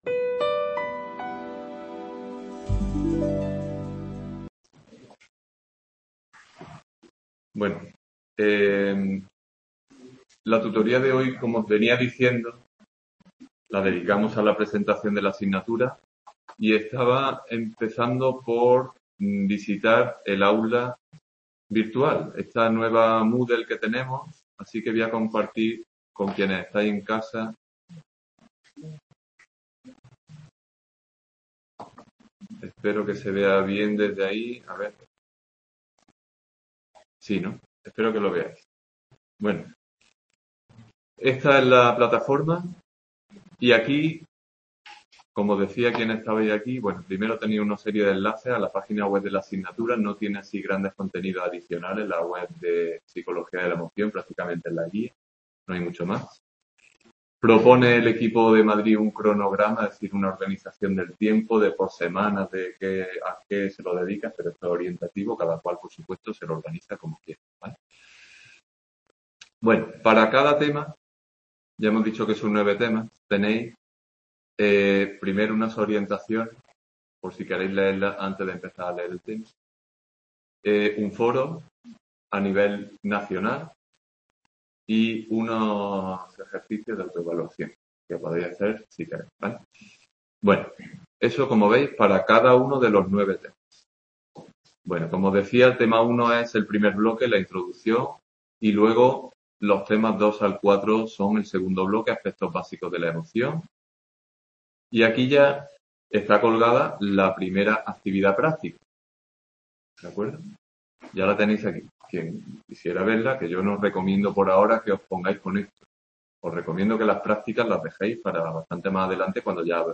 Tutoría inicial de Psicología de la Emoción en la que se presenta la asignatura para el curso 2022-23: temario, prácticas, tutorías, evaluación, etc.